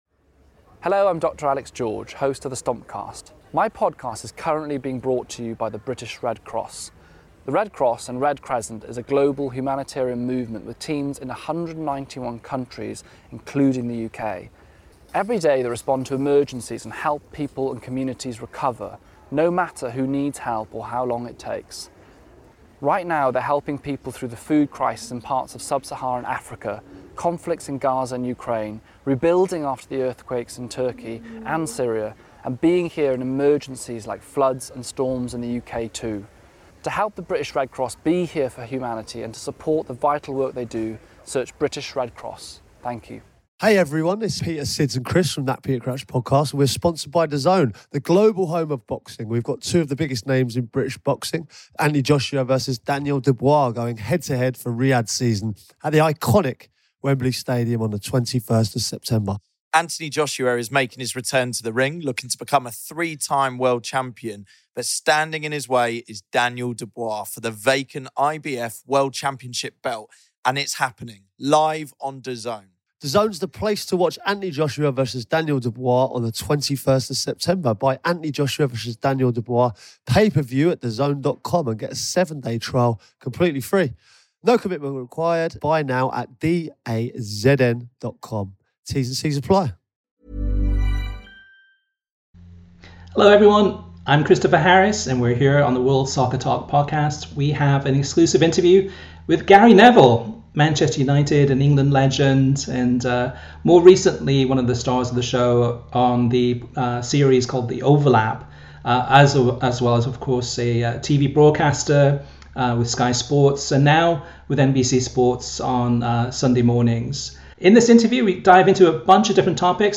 Gary Neville interview x It's Called Soccer
Gary Neville is our guest on the latest episode of the World Soccer Talk Podcast, where we interview the Manchester United and England legend about his new digital series called 'It's Called Soccer' which also includes Rebecca Lowe and Jamie Carragher.